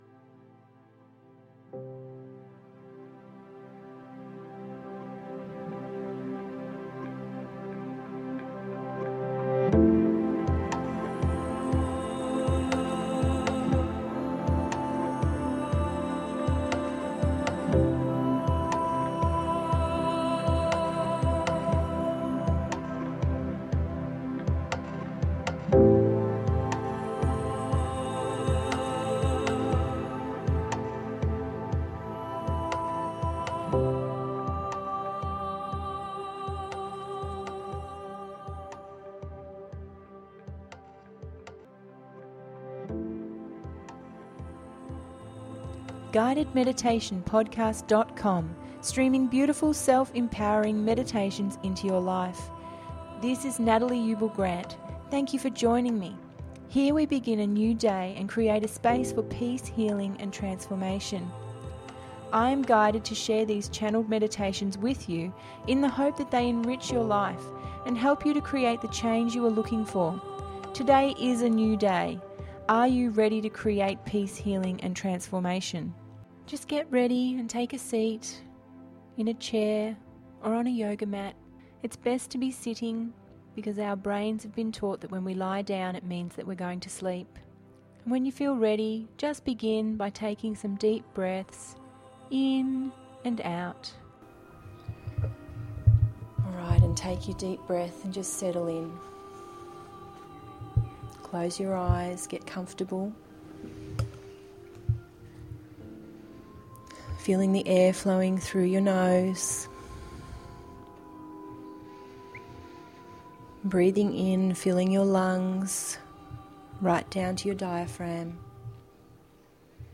Heart Meditation with Quan Yin…033
033-heart-meditation-with-quan-yin.mp3